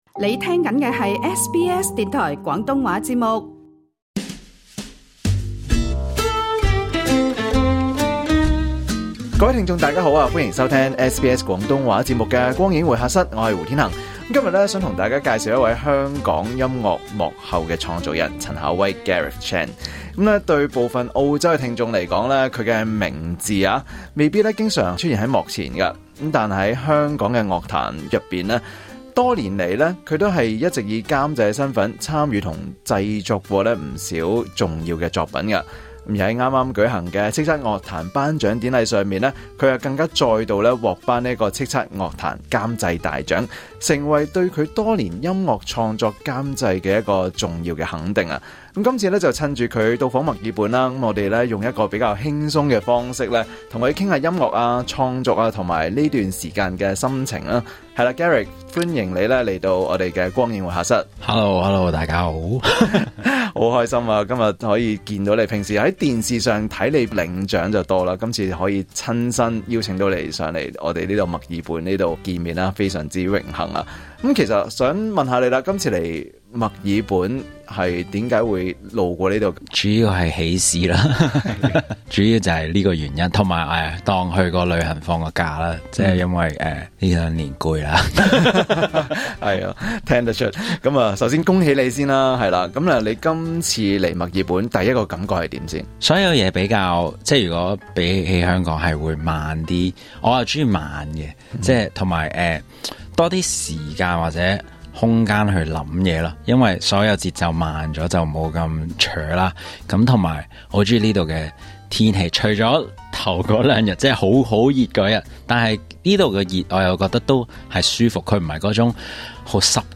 香港音樂監製陳考威在墨爾本接受SBS廣東話《光影會客室》訪問。